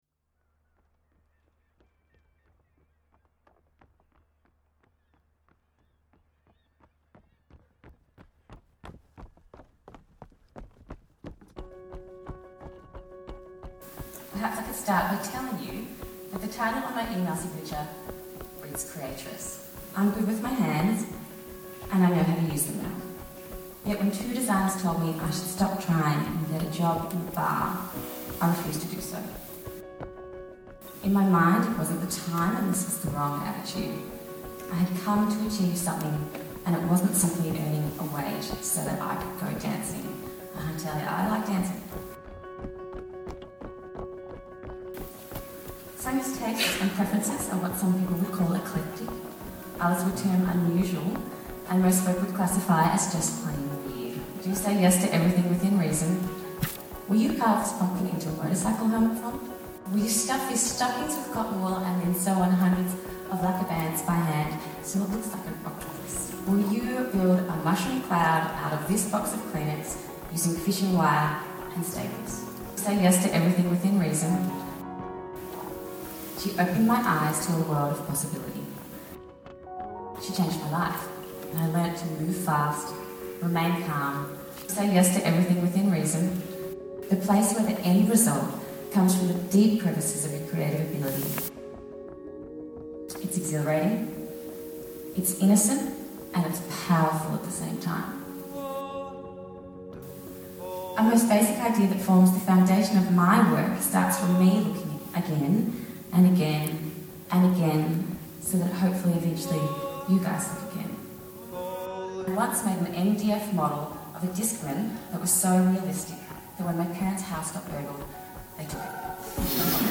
most awesome iPhone mash up